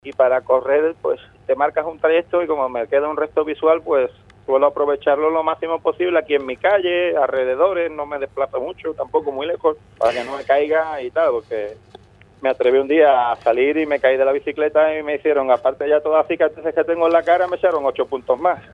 afirma sin perder el sentido del humor (formato MP3).